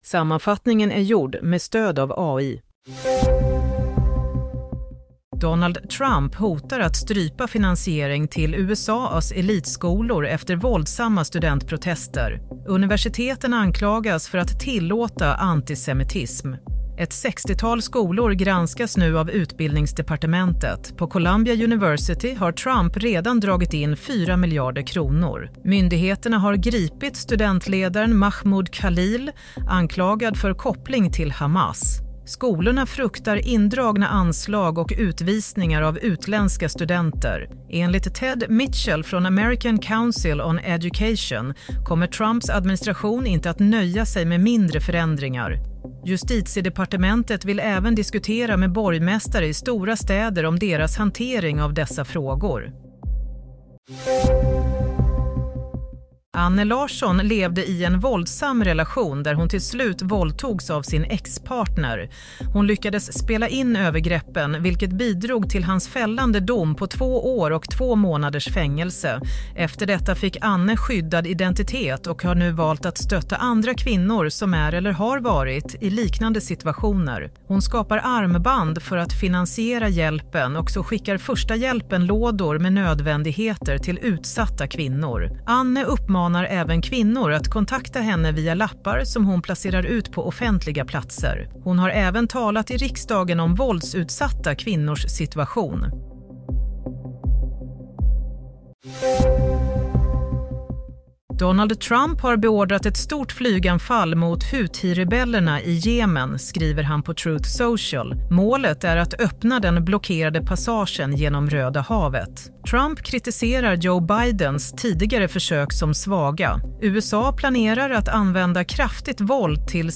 Nyhetssammanfattning - 16 mars 07:00